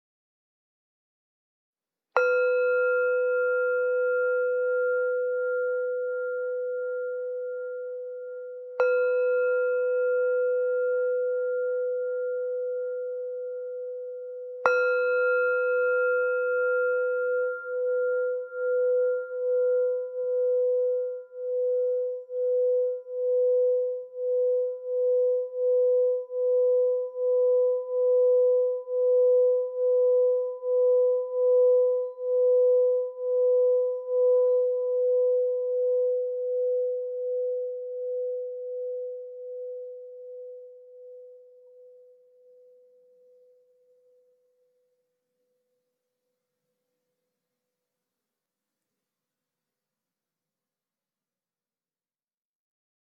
Meinl Sonic Energy Om Series Singing Bowl - 300g - Black (SB-OM-300)
The OM Series Singing Bowl is the perfect starting point for achieving a meditative, relaxed state through these ancient instruments.